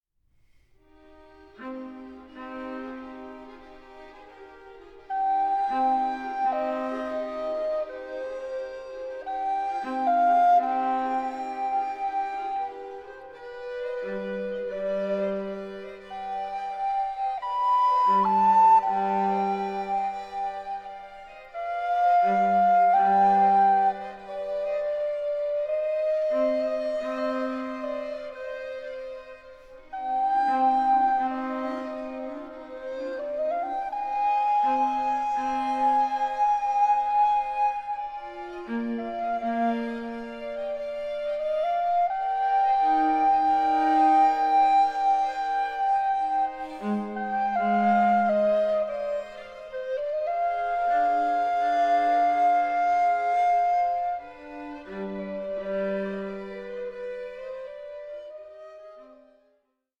Recorder player
the recorder in place of the violin